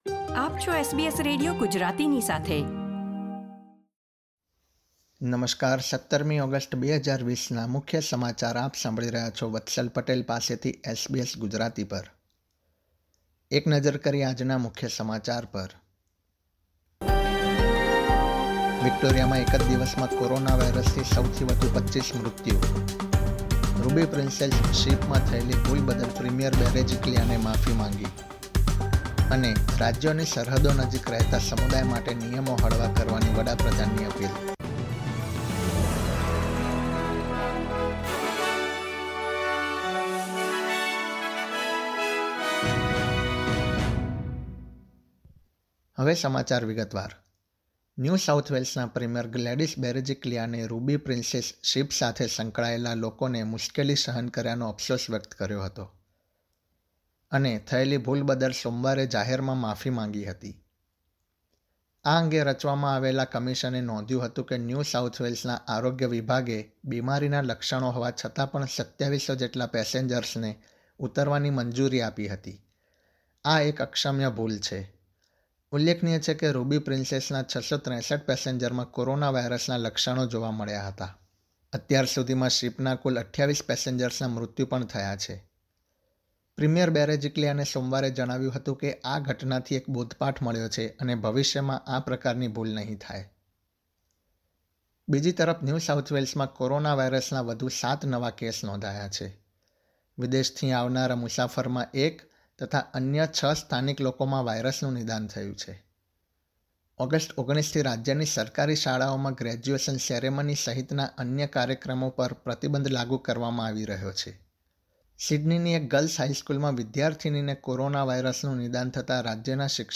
SBS Gujarati News Bulletin 17 August 2020
gujarati_1708_newsbulletin.mp3